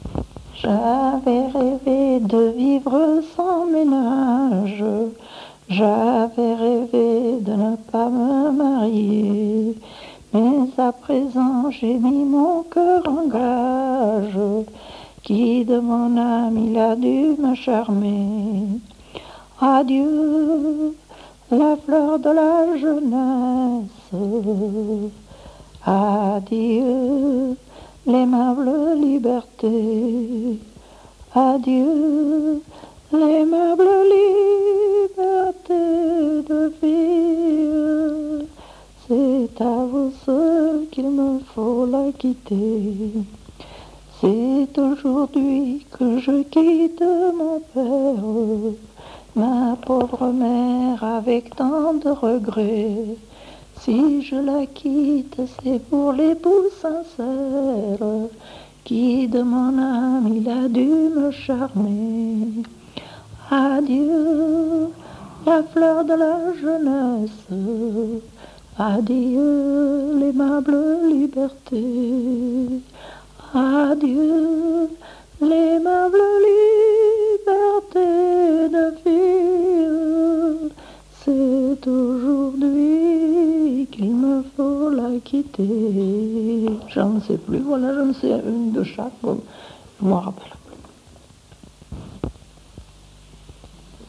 Aire culturelle : Viadène
Genre : chant
Effectif : 1
Type de voix : voix de femme
Production du son : chanté
Description de l'item : fragment ; 2 c. ; refr.